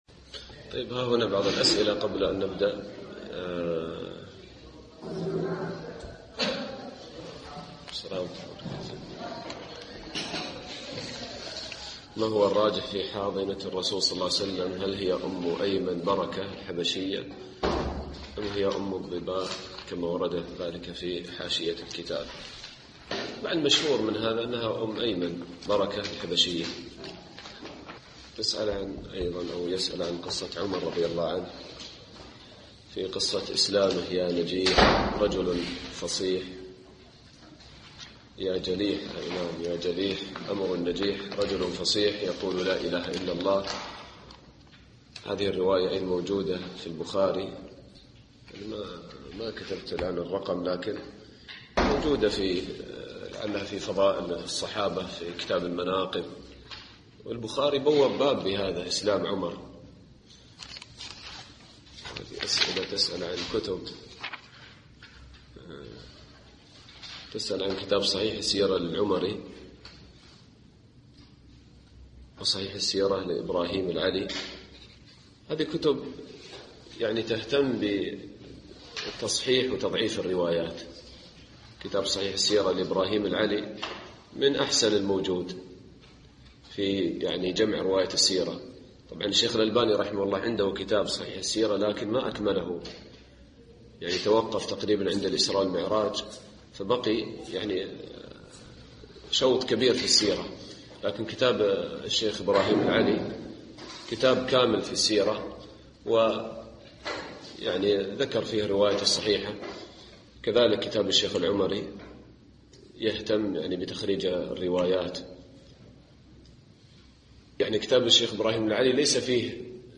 الدرس السادس